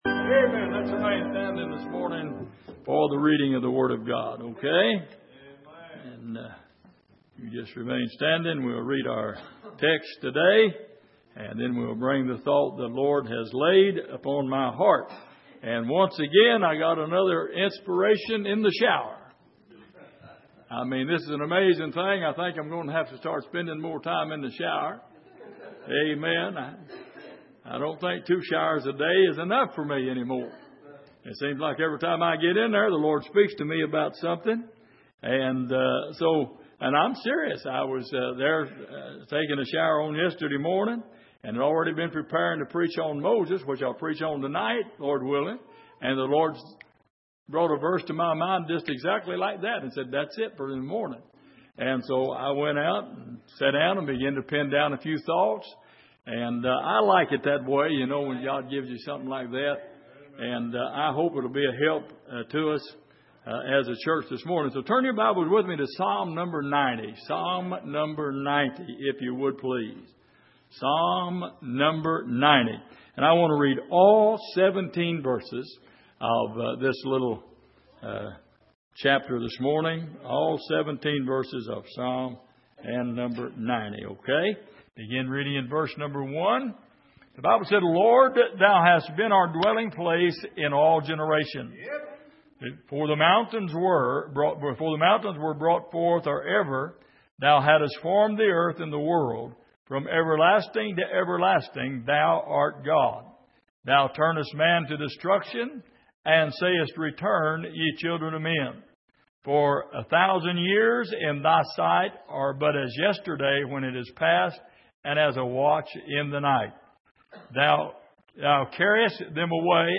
Passage: Psalm 90:1- 90:17 Service: Sunday Morning